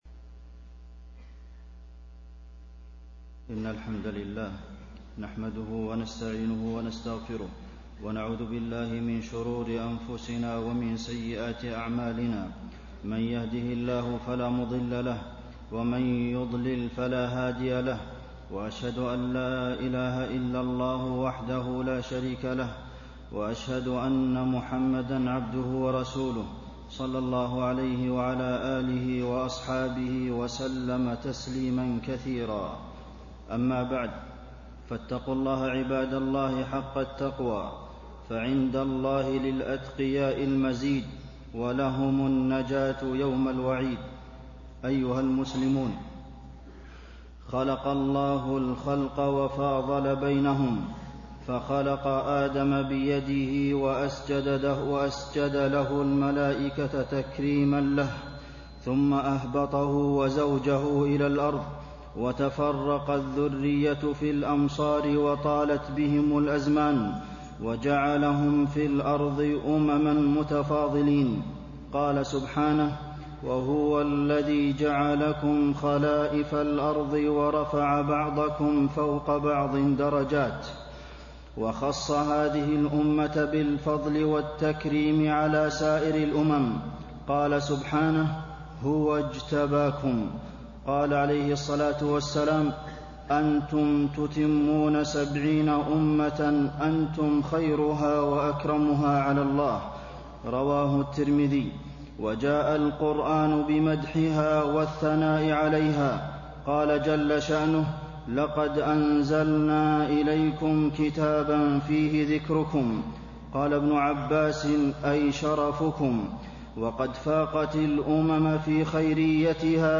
تاريخ النشر ٢٧ ربيع الأول ١٤٣٤ هـ المكان: المسجد النبوي الشيخ: فضيلة الشيخ د. عبدالمحسن بن محمد القاسم فضيلة الشيخ د. عبدالمحسن بن محمد القاسم فضل أمة الإسلام